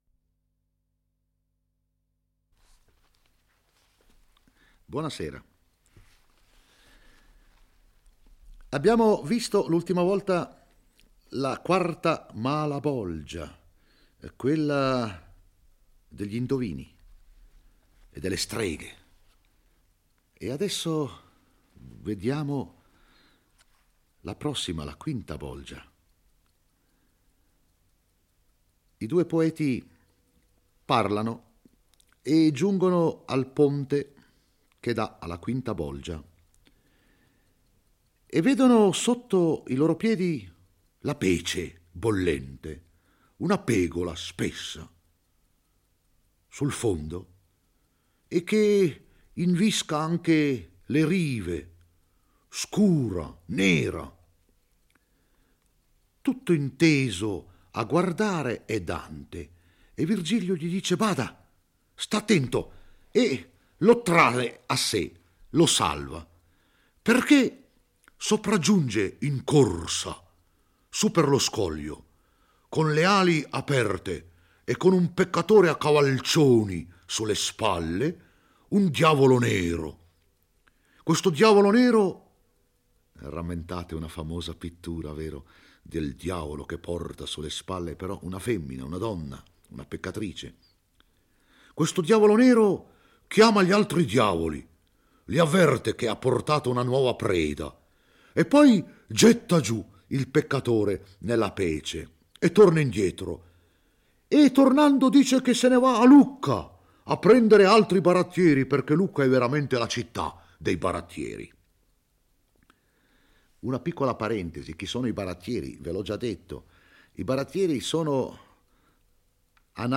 legge e commenta il XXI canto dell'Inferno. Dante e Virgilio giungono alla quinta bolgia dell'ottavo cerchio, quella dedicata ai barattieri: astuti truffatori vissuti di inganni e raggiri, approfittatori della posizione politica e delle cariche pubbliche per trarre illeciti guadagni.